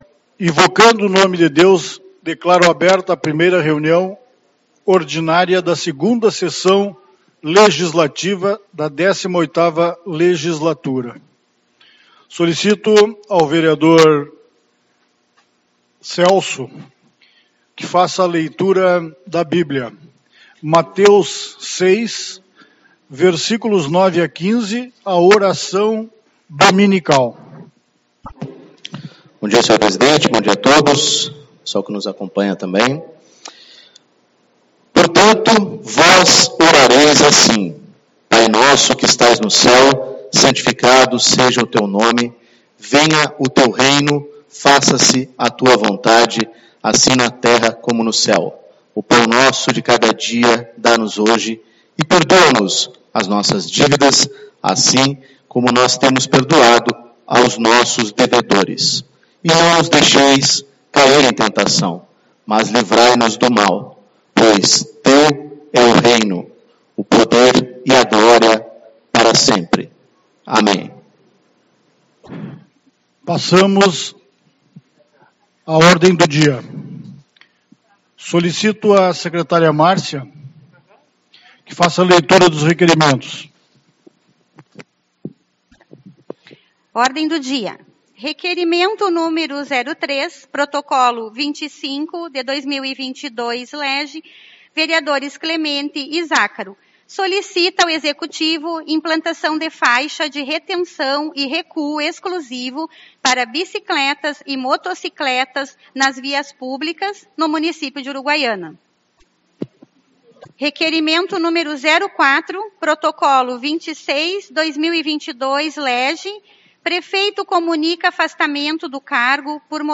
03/02 - Reunião Ordinária